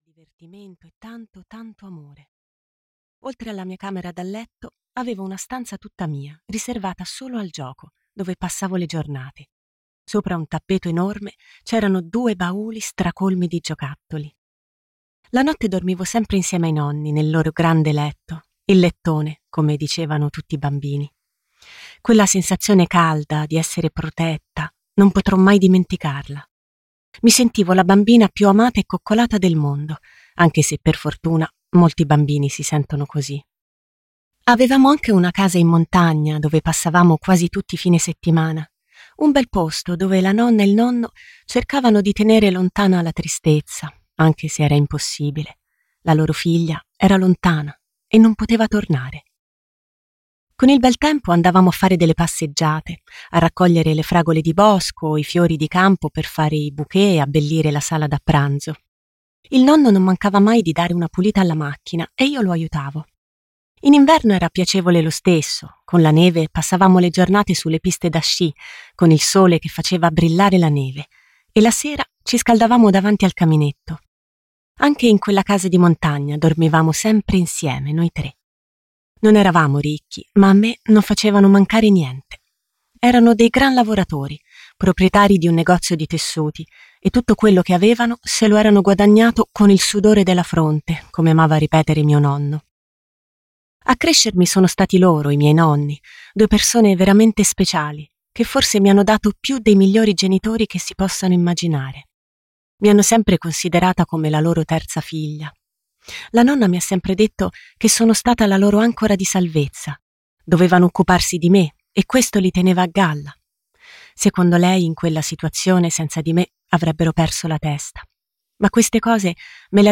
"Occhi di bambina" di Marco Vichi - Audiolibro digitale - AUDIOLIBRI LIQUIDI - Il Libraio